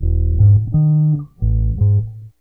BASS 30.wav